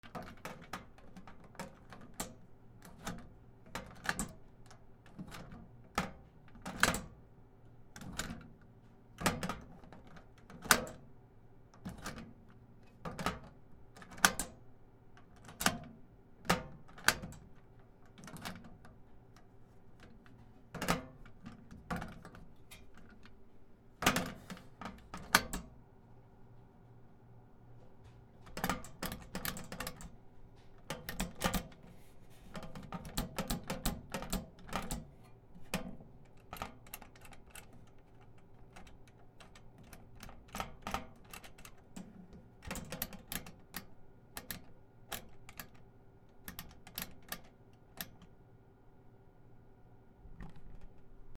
小さい金庫
カチャカチャ D50